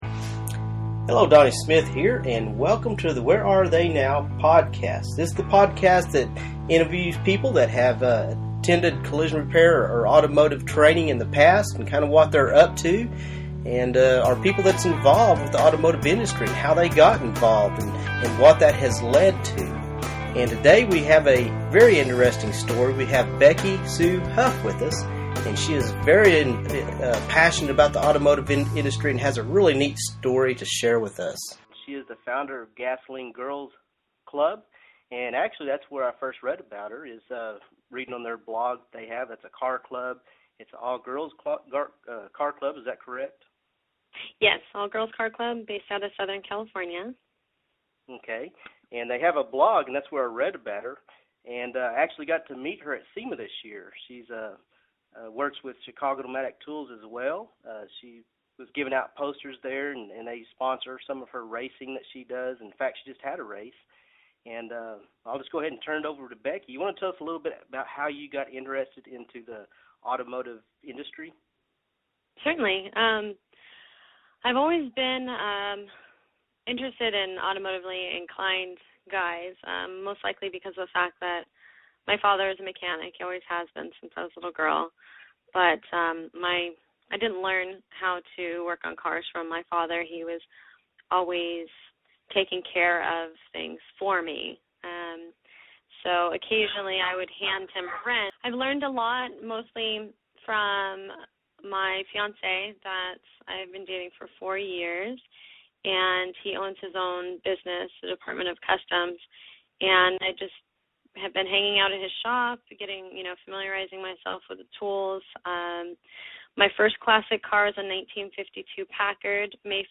Listen To This Interview Podcast